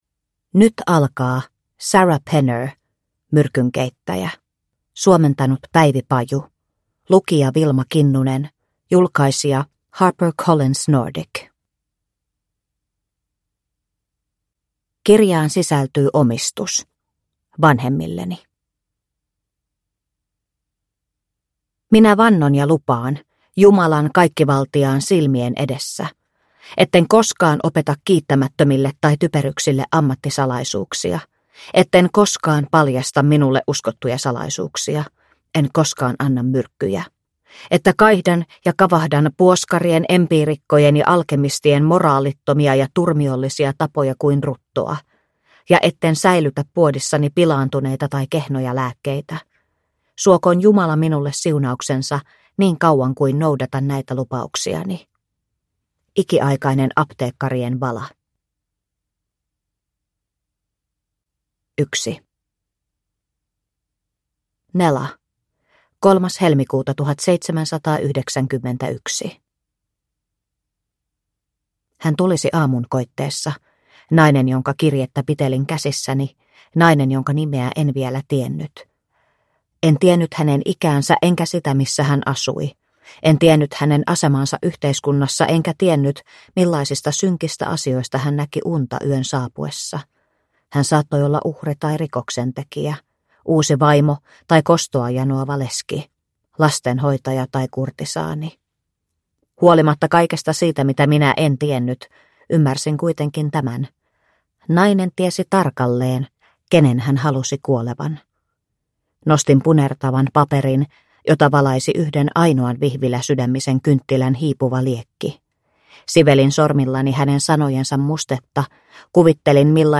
Myrkynkeittäjä – Ljudbok – Laddas ner